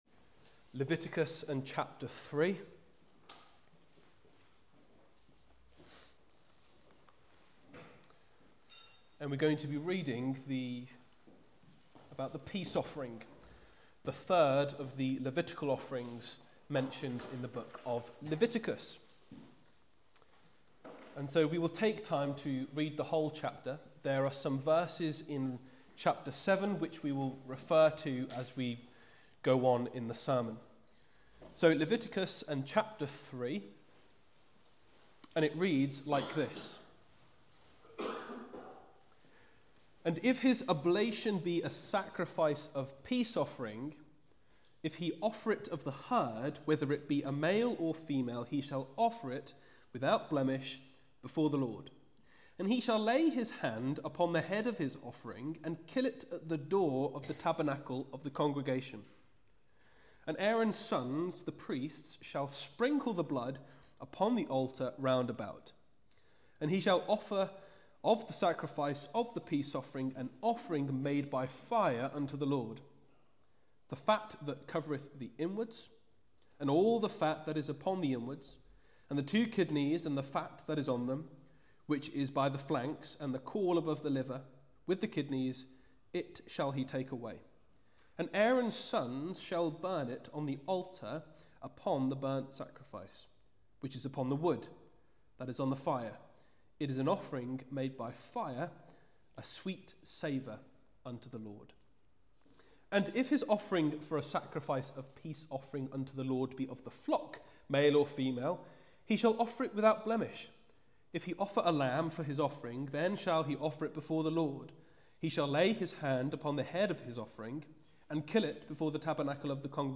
Listen to this sermon about how this offering relates to us, but more importantly, how it reminds us of the ultimate offering: Jesus Christ.